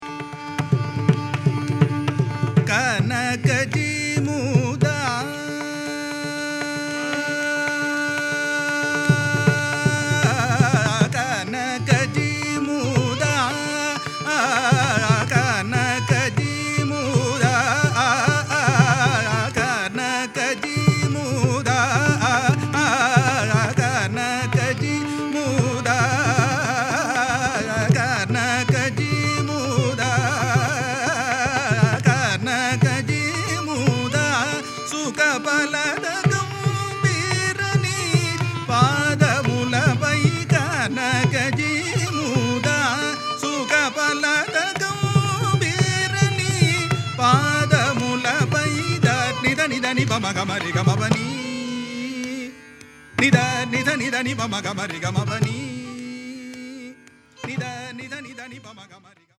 voice
mrdangam